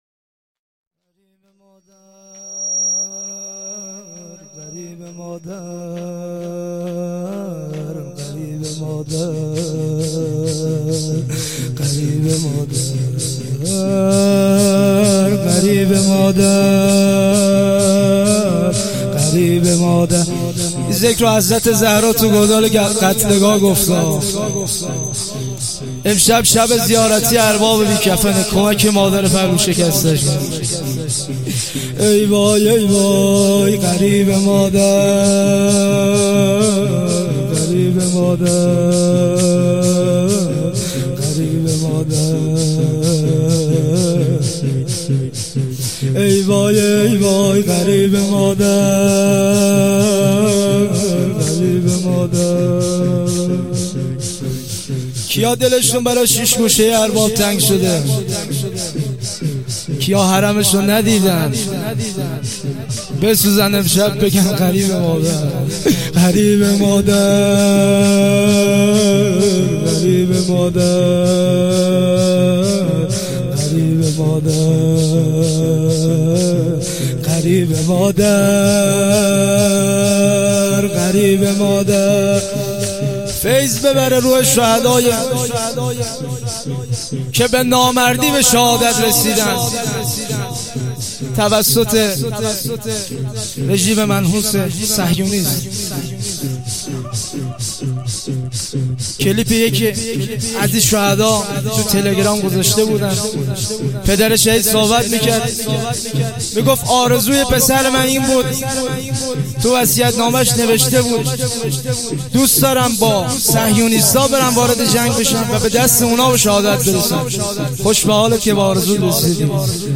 شهادت امام موسی کاظم (ع) 97.1.23،شور و روضه پایانی